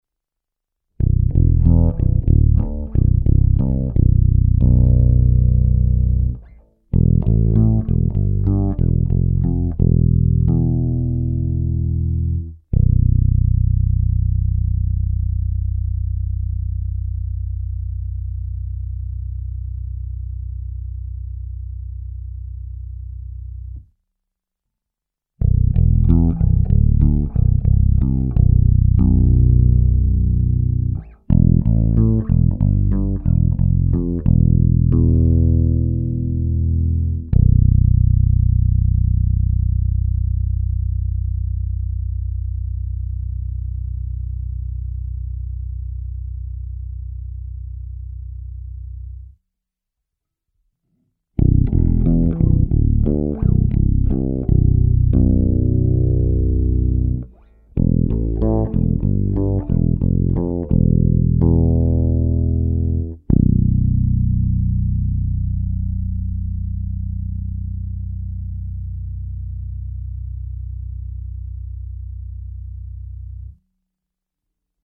Nahrávka s použitím struny H
Tak než tu basu vrátím, tak jsem tam ještě narychlo hluboko něco zabrumlal.